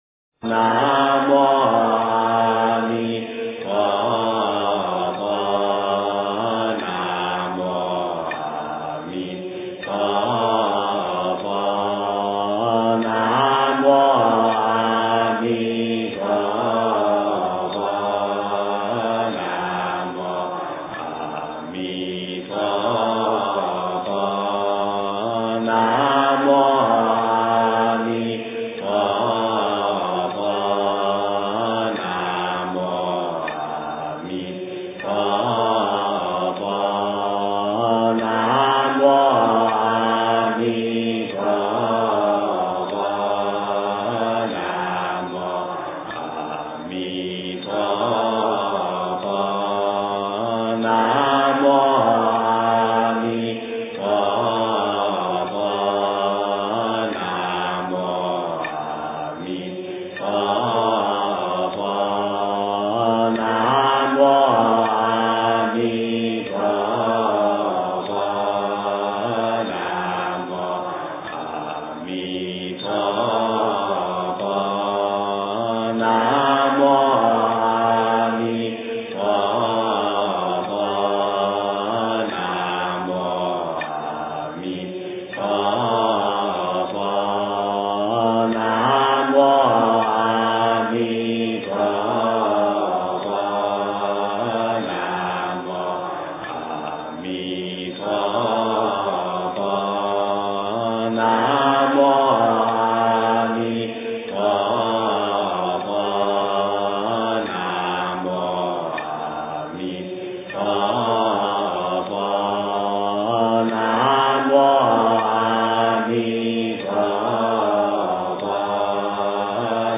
经忏